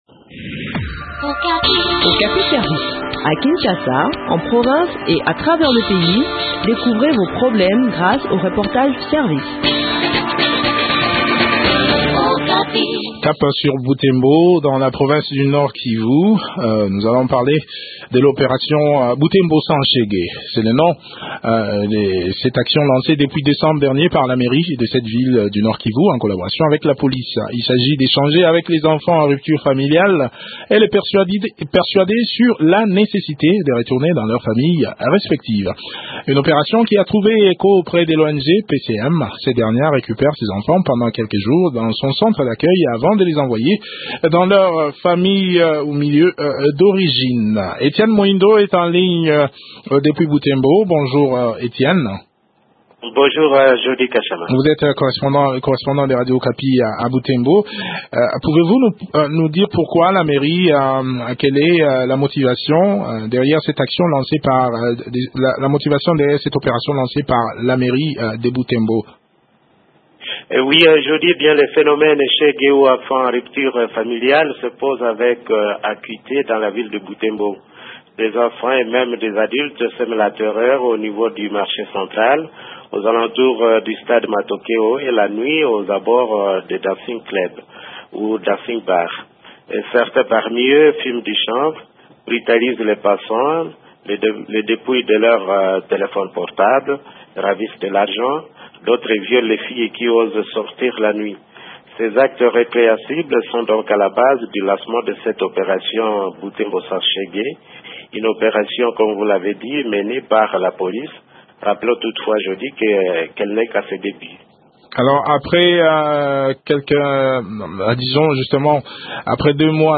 Le point sur l’exécution de cette opération dans cet entretien